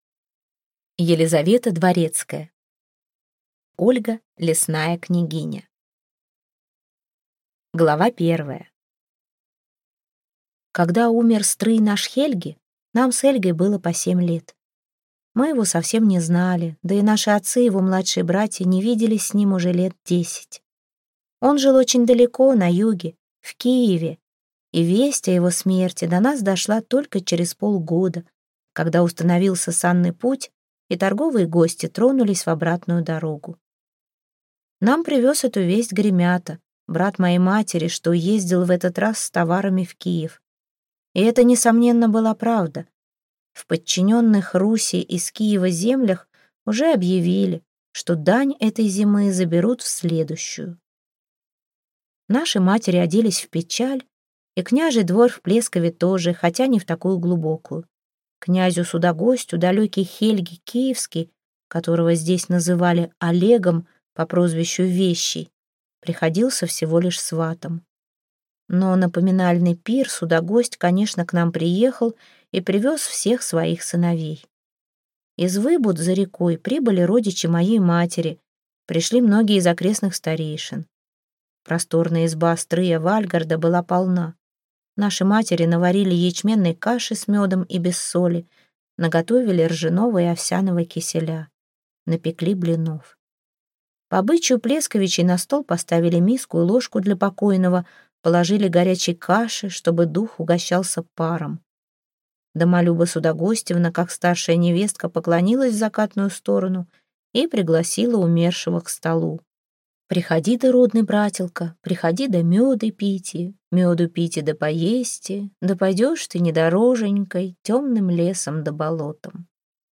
Аудиокнига Княгиня Ольга. Невеста из чащи | Библиотека аудиокниг